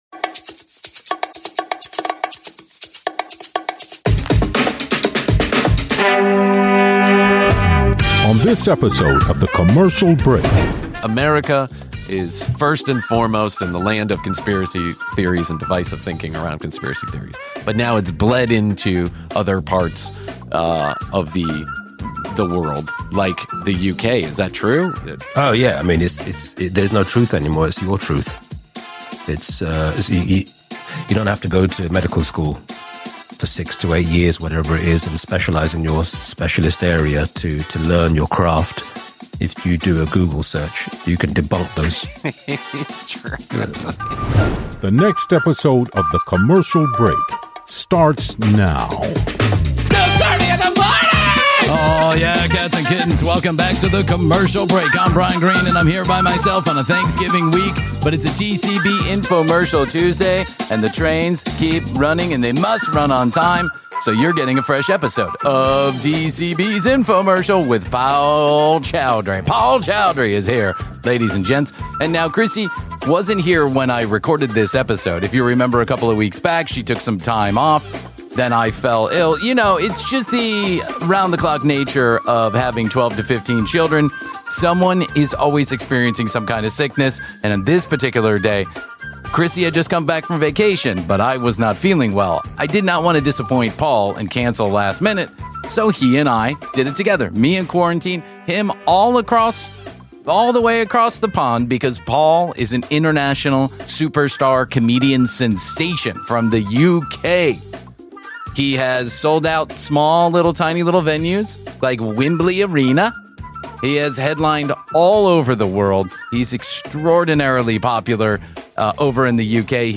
Comedian Paul Chowdhry drops into The Commercial Break for a fast, sharp, and wildly funny conversation that swerves between identity, fame, and the strange circus that is modern stand-up. Paul talks about becoming the first British Asian comic to sell.